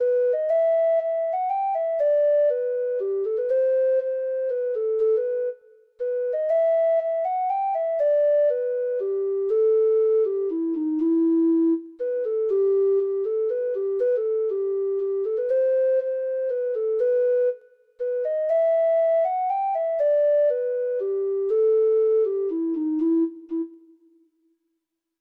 Free Sheet music for Treble Clef Instrument
Traditional Music of unknown author.
Treble Clef Instrument Sheet Music
Irish